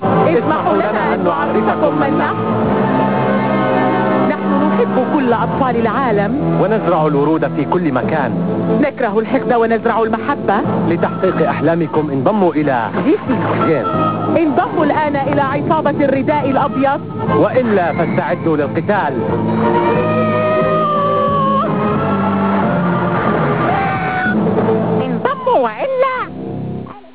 アラビア語版ロケット団の口上　(wav : 212KB)
ムサシがオバさんくさかったですねえ。
犬山：ちょっと年齢度高い感じですね。
犬山：あと、せわしないですね。